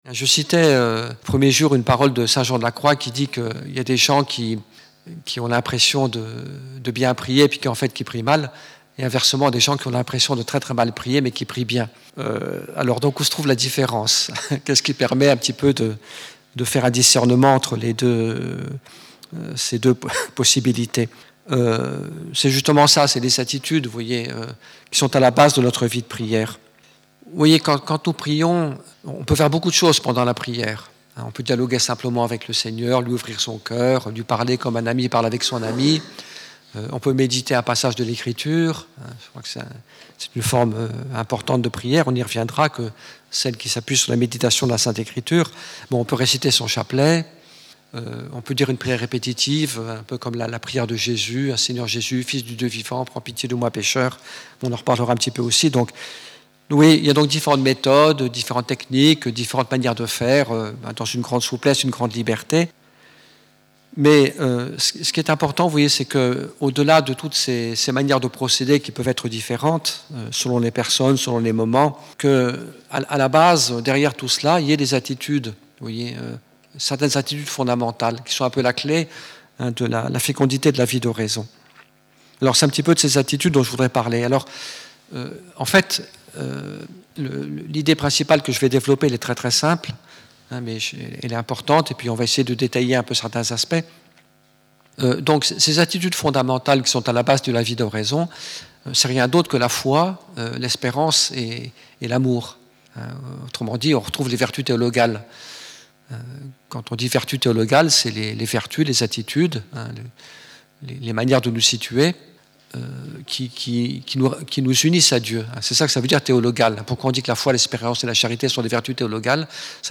Un CD MP3* regroupant 6 enseignements: